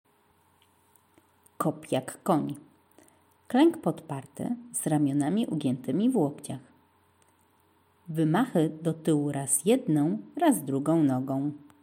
koń